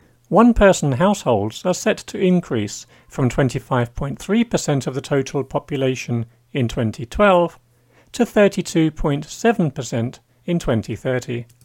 Breaking News English | Dictation | Online Dining